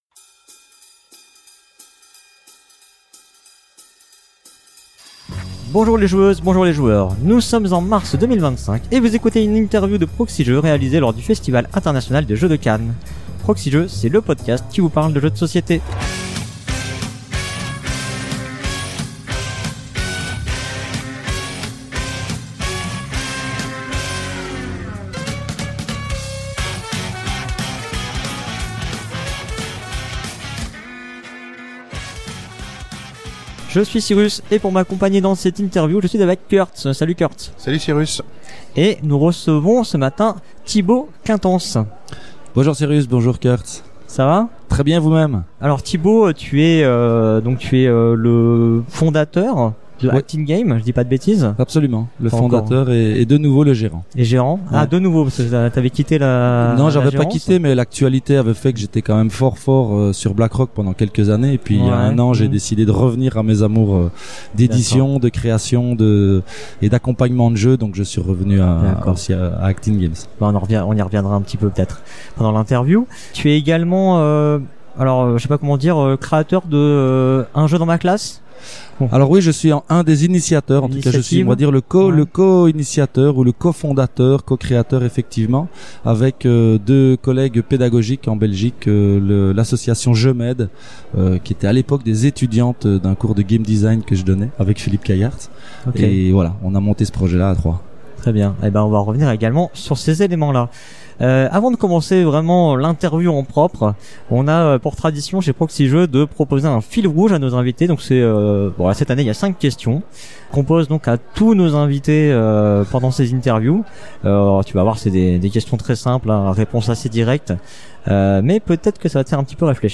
Interview
réalisée lors du Festival International des Jeux de Cannes 2025